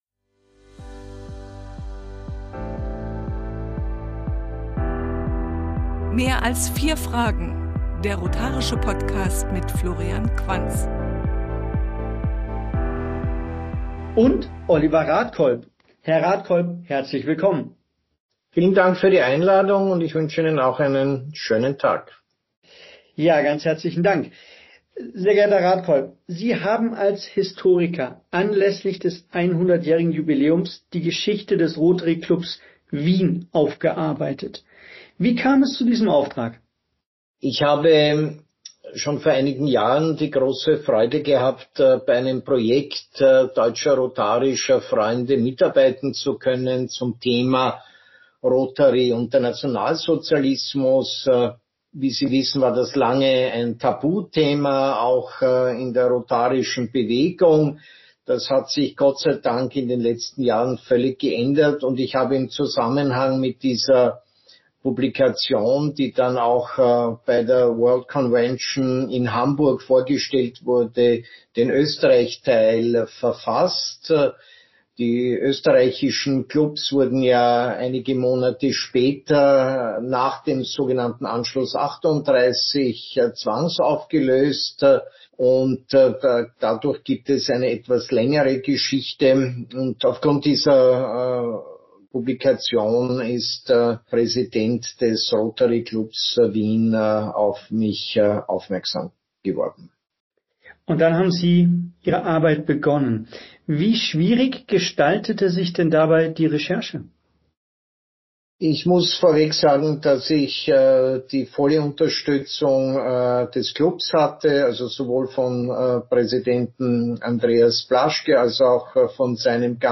Der Historiker spricht über die wichtige Aufarbeitung von rotarischer Club-Geschichte.